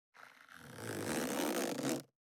410,ジッパー,チャックの音,洋服関係音,ジー,バリバリ,カチャ,ガチャ,シュッ,パチン,ギィ,カリ,カシャ,スー,
ジッパー効果音洋服関係